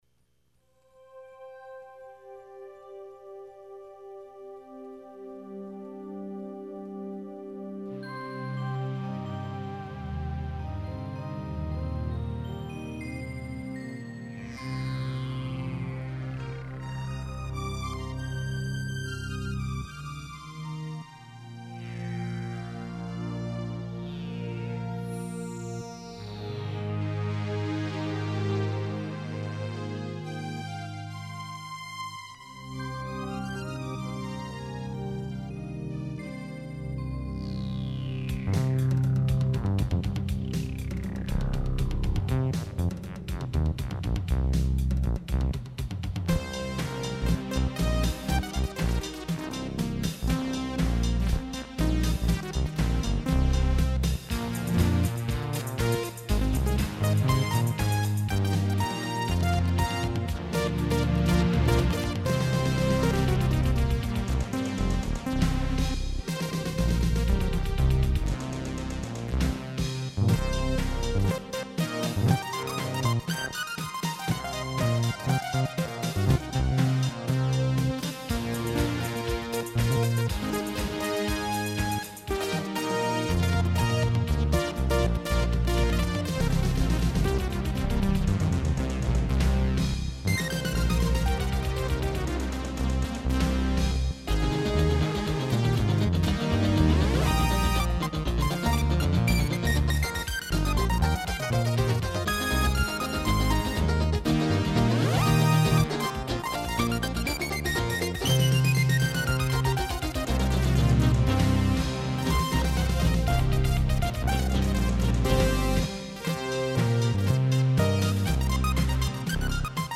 Upbeat, almost Techno